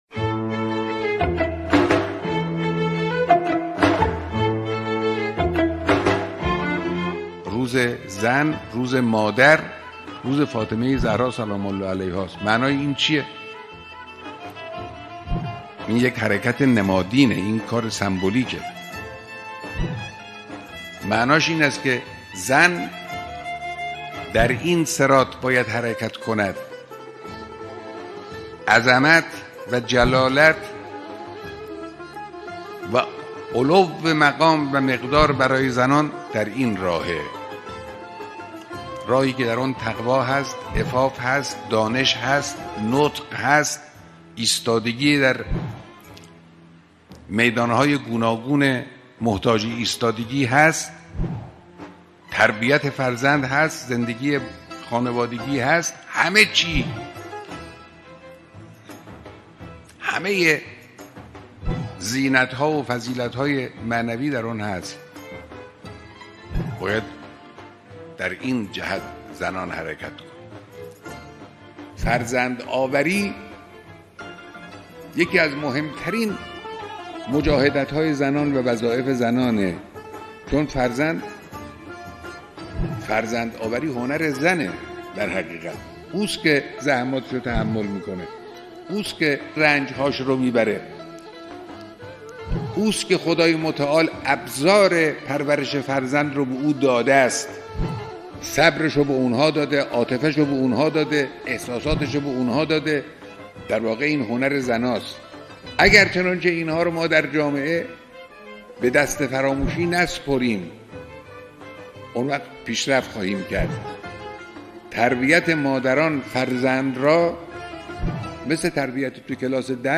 صوت | گزیده بیانات رهبری در مورد مقام زن